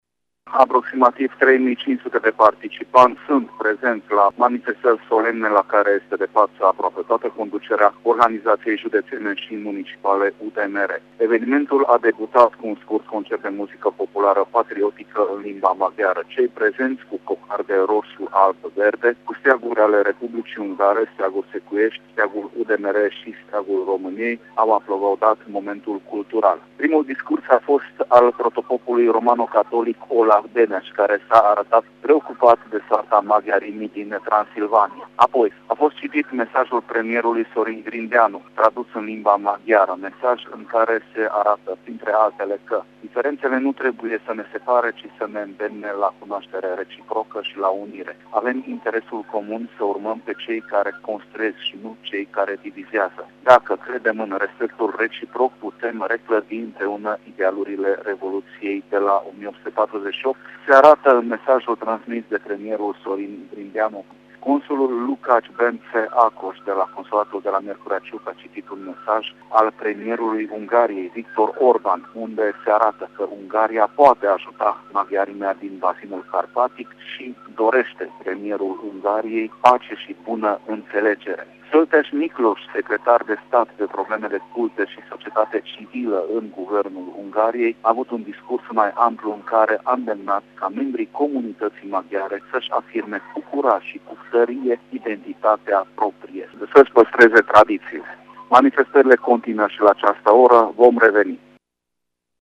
Primele activități se derulează în această după-amiză la Monumentul Secuilor Martiri din localitate.